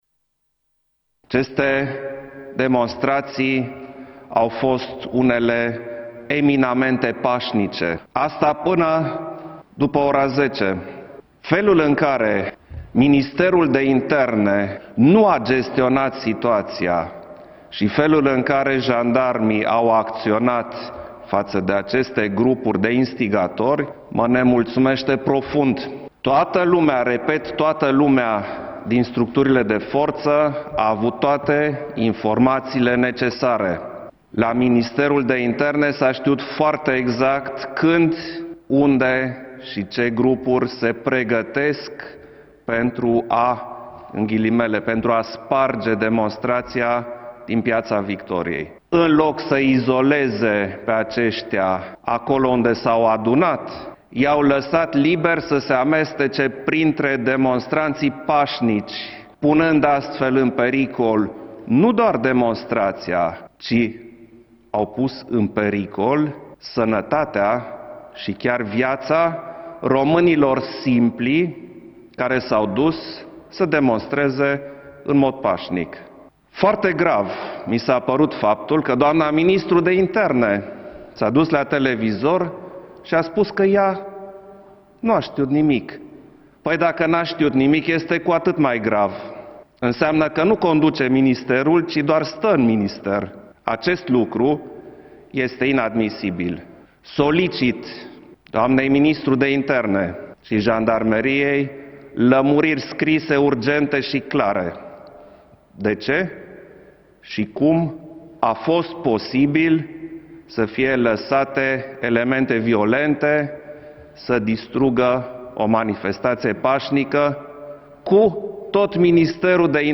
Klaus Iohannis și-a exprimat public nemulțumirea vizavi de situația creată aseară când un grup de protestatari din capitală s-au manifestat violent și solicită Ministerului de Interne și Jandarmeriei lămuriri urgente și clare: